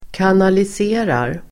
Uttal: [kanalis'e:rar]